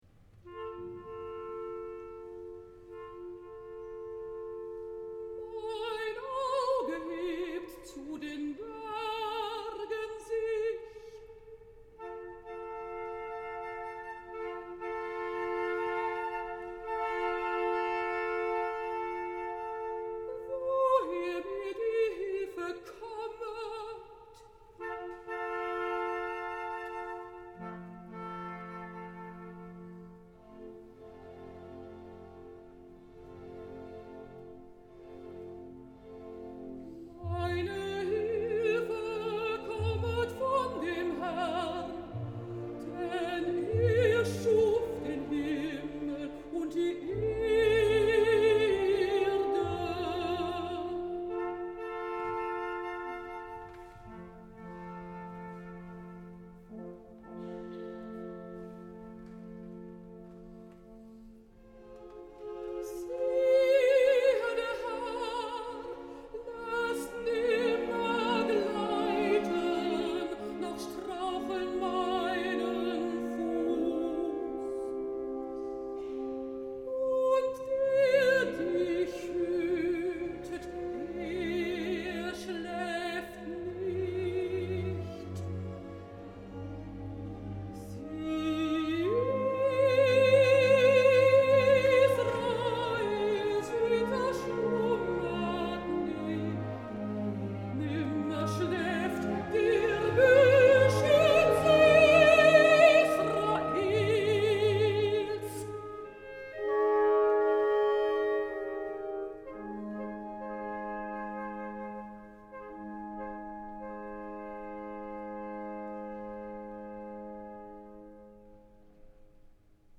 Biblische Lieder von Antonín Dvořák – Mein Auge hebt zu den Bergen sich / Juli 2010 im Michel Hamburg